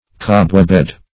Cobwebbed \Cob"webbed`\, a.